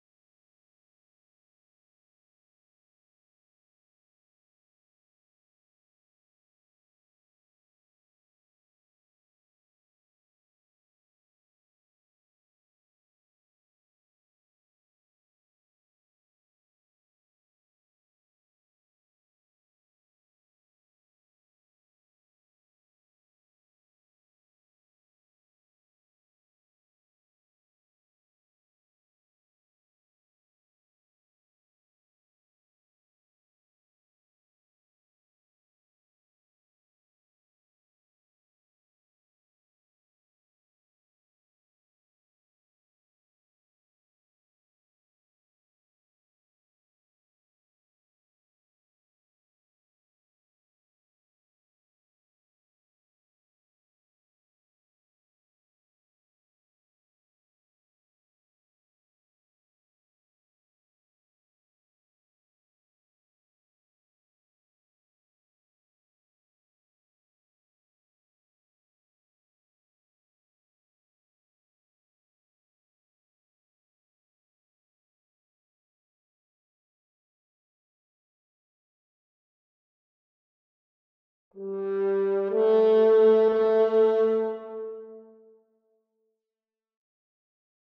1. Flute (Flute/Auto)
4. Oboe (Oboe/Auto)
6. English Horn (English Horn/Auto)
7. Clarinet (Clarinet/Auto)
10. Bassoon (Bassoon/Auto)
12. French Horn (Horn/Auto)
16. Trumpet (Trumpet/Auto)
17. Trombone (Trombone/Auto)
19. Tuba (Tuba/Auto)
20. Timpani (Timpani/Auto)
22. Ensemble Strings (Violins section/Auto)
24. Ensemble Strings (Violas section/Auto)
25. Ensemble Strings (Cellos section/Auto)
26. Ensemble Strings (Contrabasses section/Auto)